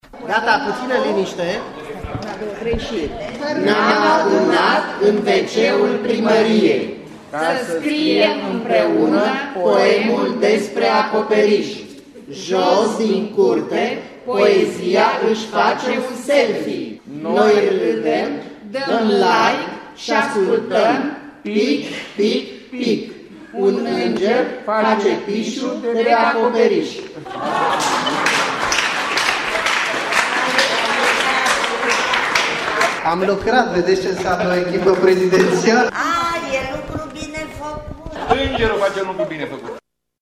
Sub acoperişul Primăriei Sighişoara